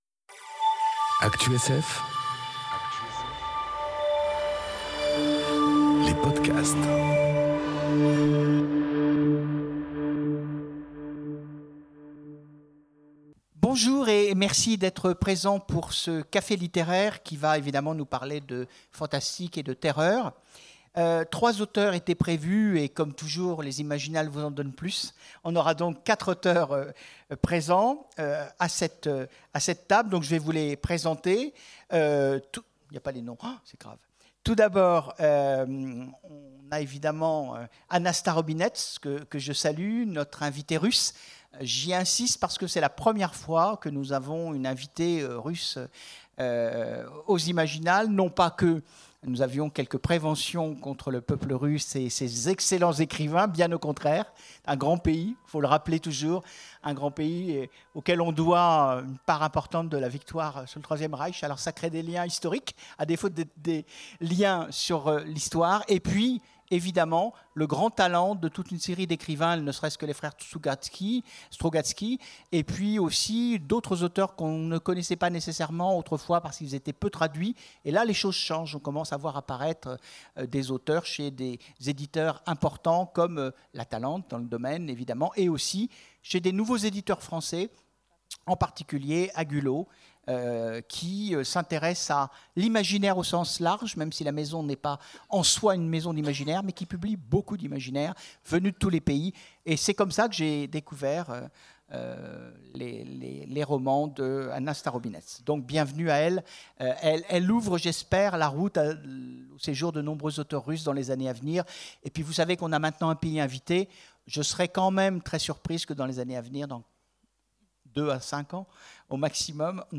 Conférence Créatures surnaturelles... Ou puissance du rêve enregistrée aux Imaginales 2018